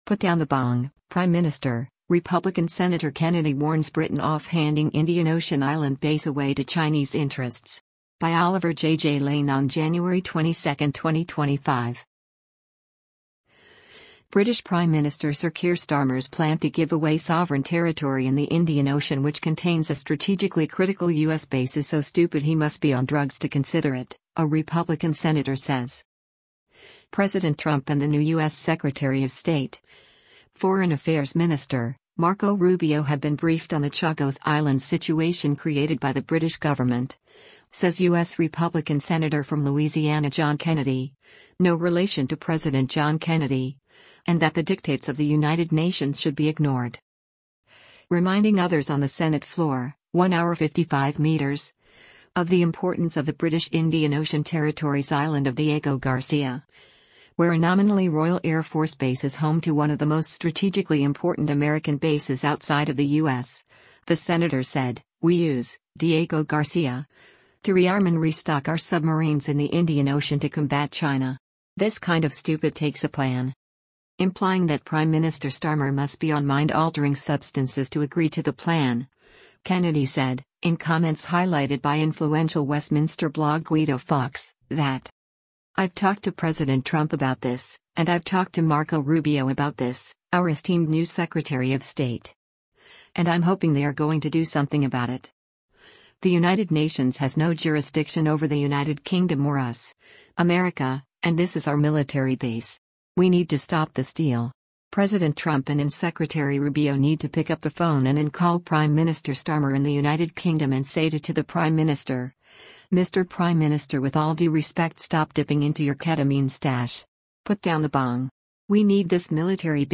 Reminding others on the Senate floor (1hr 55m) of the importance of the British Indian Ocean Territory’s island of Diego Garcia, where a nominally Royal Air Force base is home to one of the most strategically important American bases outside of the U.S., the Senator said: “we use [Diego Garcia] to rearm and restock our submarines in the Indian Ocean to combat China.